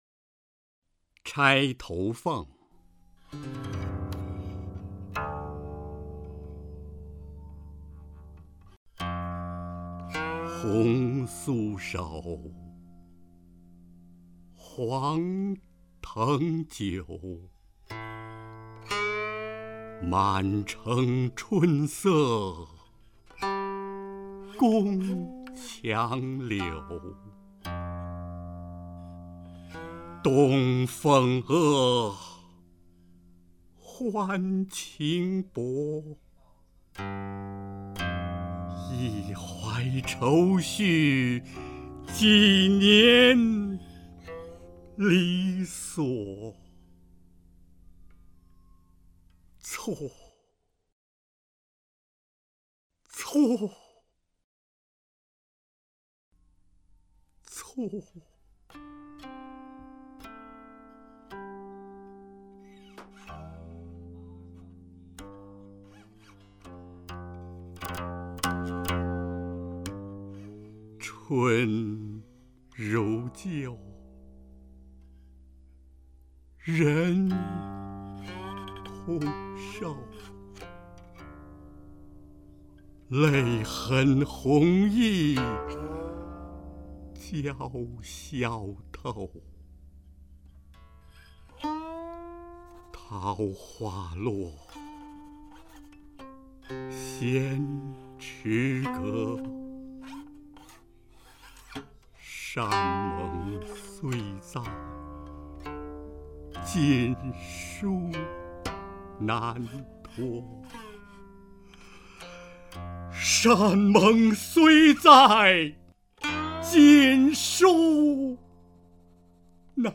配乐朗诵陆游作品－－铁马冰河